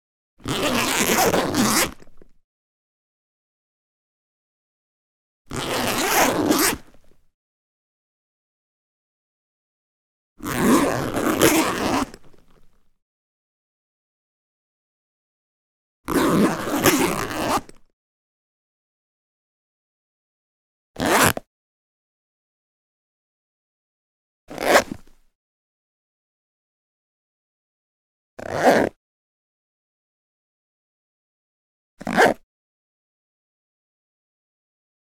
household
Camera Soft Leather Case Open Zipper